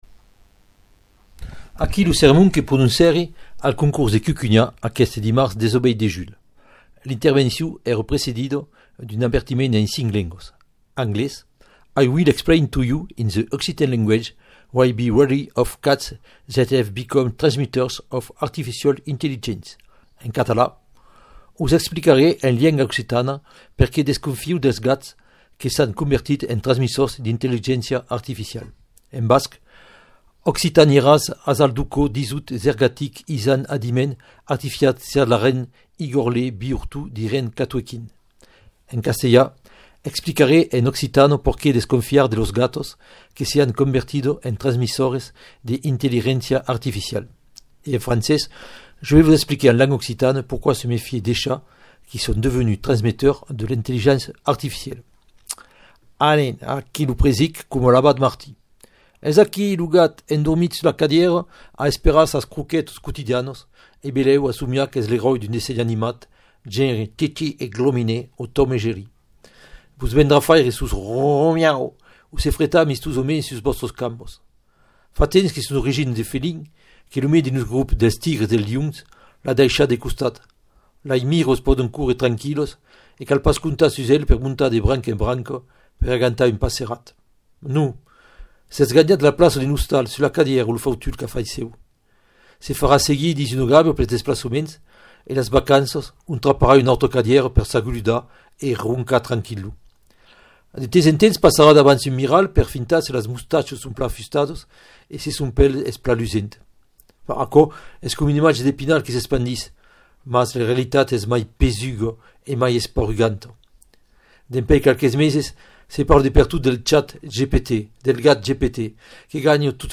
Aquí lo sermon que prononcèri al concors de Cucunhan aqueste dimars 18 de julh.